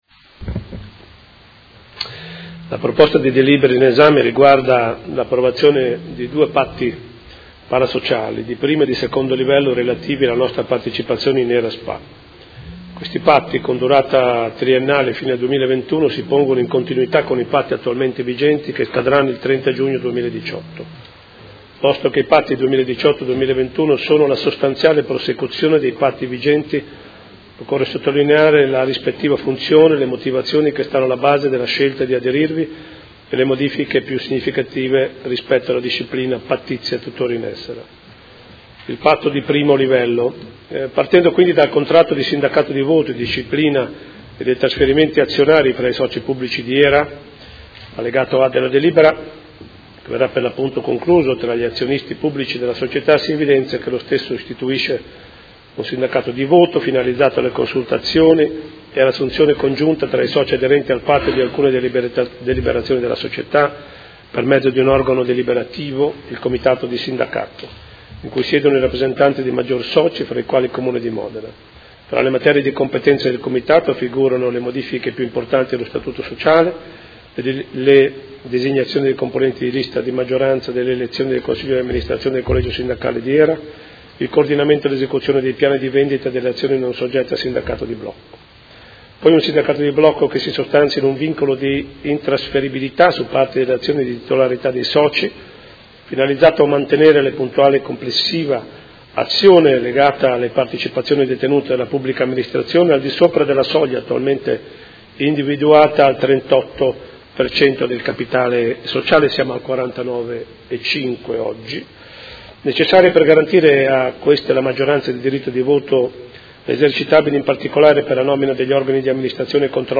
Sindaco — Sito Audio Consiglio Comunale
Seduta del 5/04/2018. Proposta di deliberazione: Conclusione di Patti parasociali fra i Soci pubblici e fra i Soci pubblici di Area modenese di HERA S.p.A.